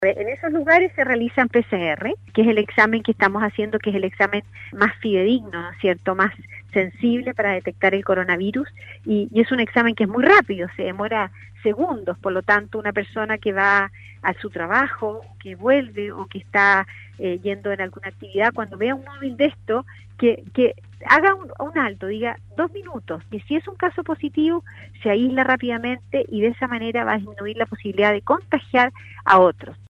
En conversación con Radio Sago, la Subsecretaria de Salud Pública, Paula Daza se refirió a el Plan de Búsqueda Activa de Covid-19 en inmediaciones de Establecimientos educacionales.